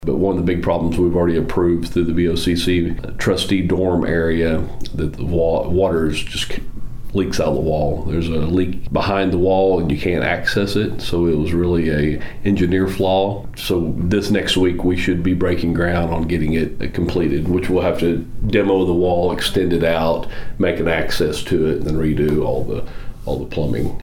Perrier talks about a plumbing issue